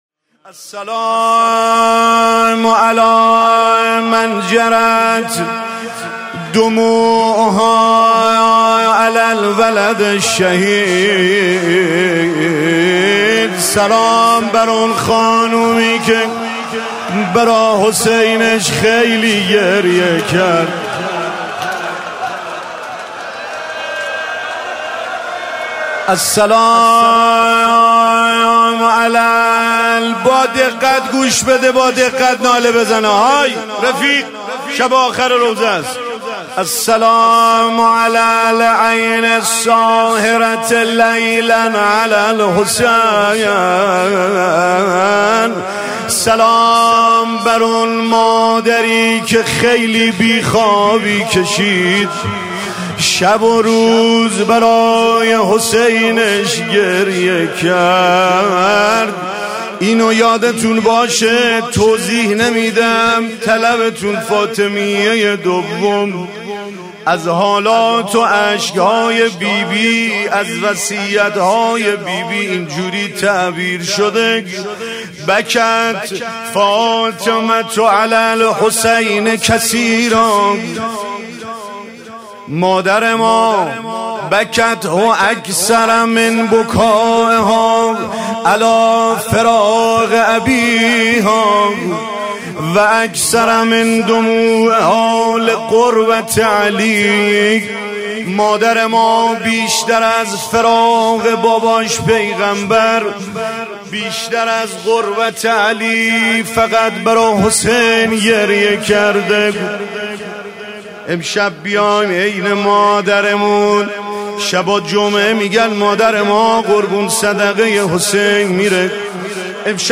روضه حضرت زهرا – محتوانشر